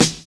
DISCO 14 SD.wav